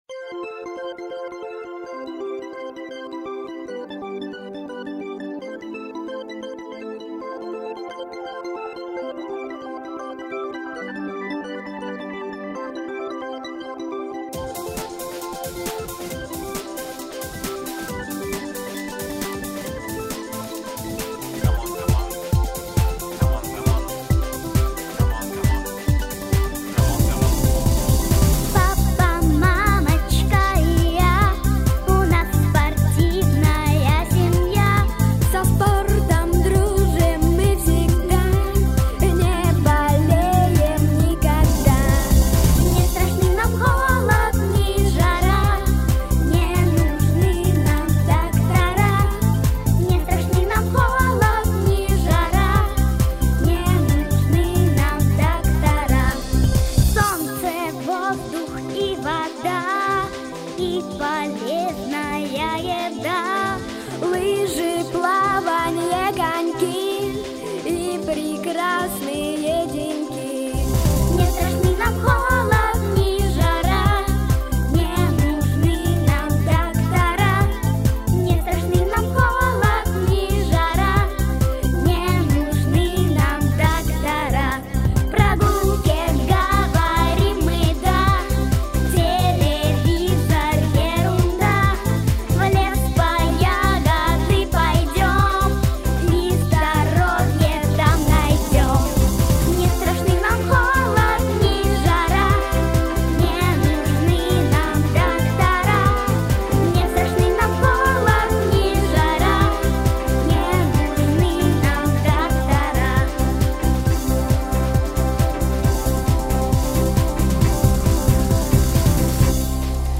Песенка для музыкальной заставки на спортивном празднике.